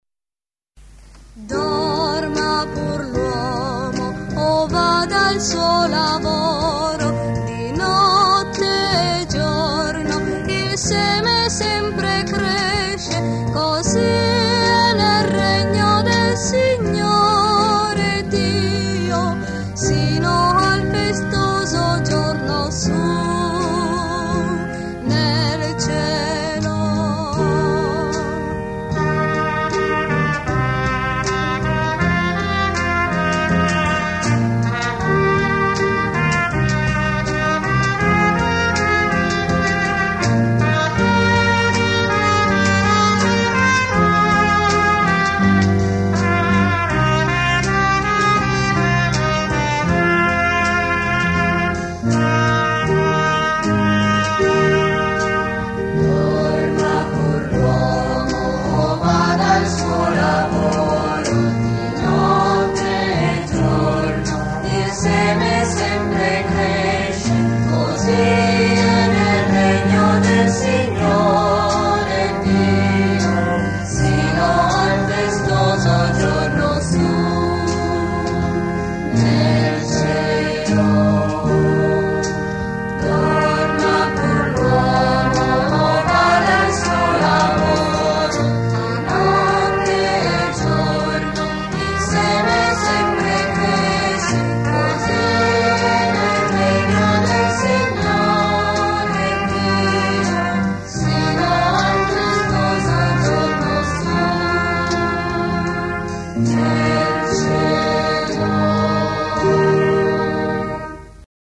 Canto per la Decina di Rosario e Parola di Dio: Dorma pur l’uomo